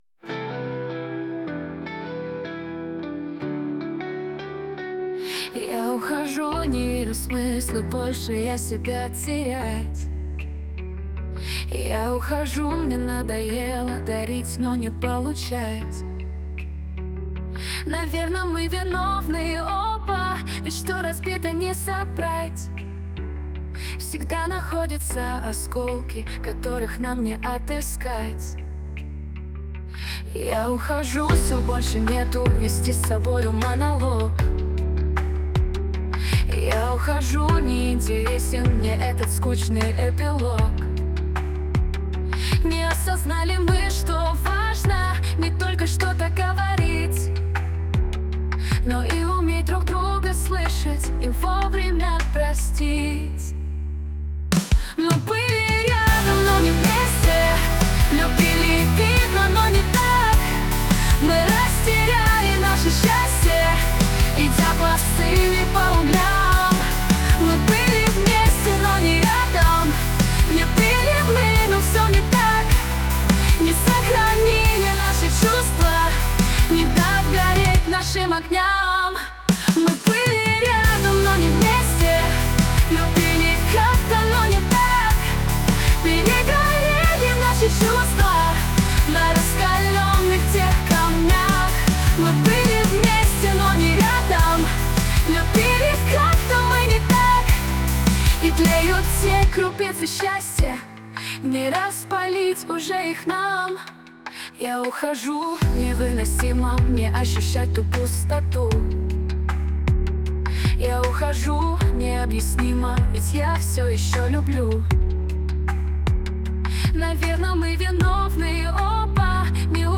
ТИП: Пісня
СТИЛЬОВІ ЖАНРИ: Ліричний
Скажу, що неймовірно гарний вірш-пісня, чуттєвий і сумний до глибини душі.